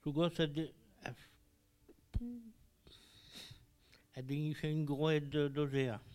ambiance et archive
Catégorie Locution